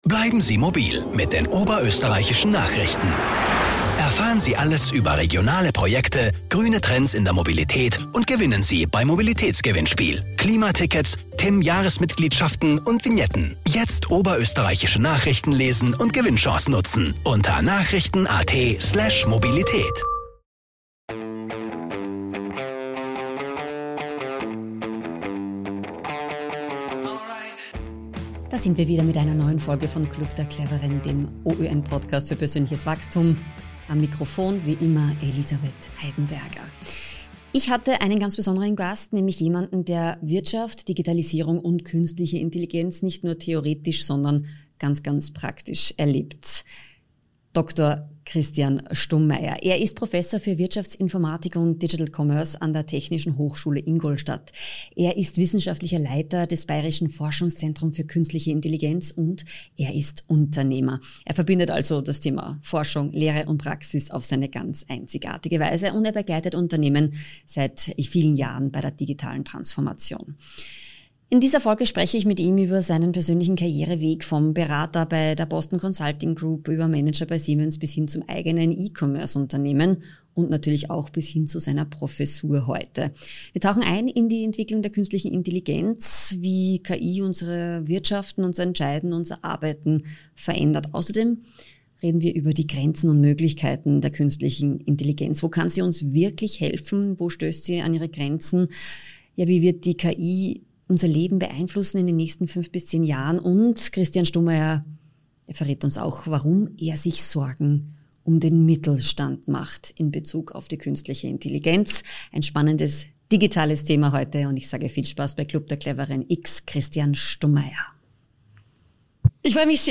spricht im Podcast-Interview über die Komplexität der KI, ihre Grenzen und warum gutes "Prompten" in Zukunft gar nicht mehr so entscheidend sein wird.